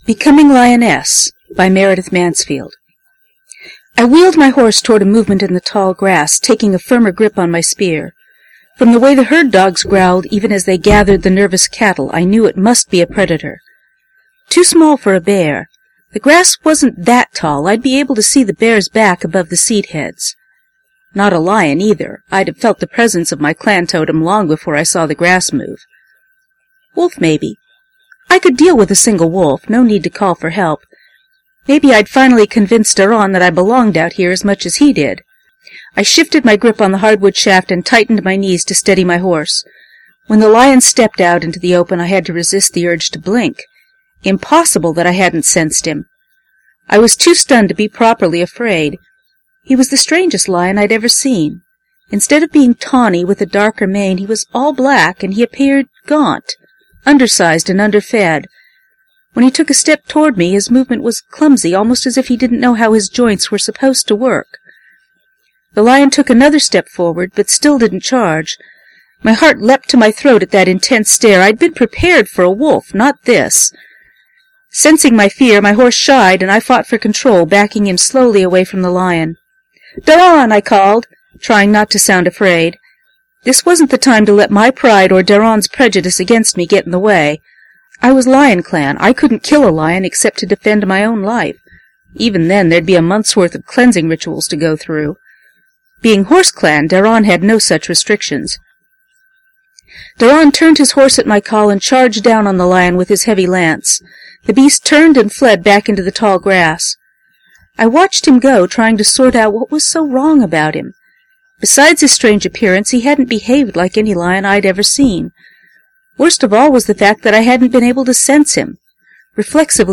It’s a long way from perfect, yet. There are still a couple of places where you can hear me stumble–over my own writing.
Also, the last third or so of the recording you can hear my voice starting to get hoarse again.